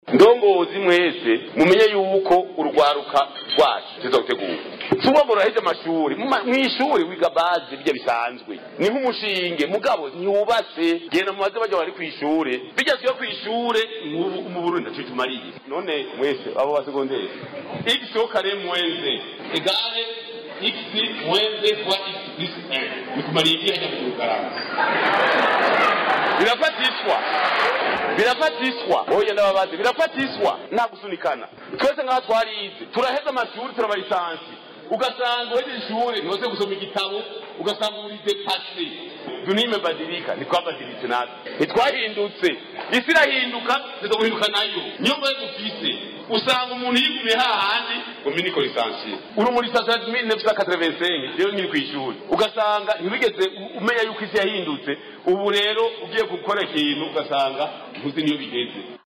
Lundi 31 octobre à Makamba, le Secrétaire général du parti Cndd-Fdd, Révérien Ndikuriyo, a clôturé une formation de 6 mois sur l’auto-développement à l’intention des jeunes venus de toutes les provinces du pays, regroupés au sein de l’association’’Eagle Security Services’’. A cette occasion, il a exprimé sa vision de l’éducation.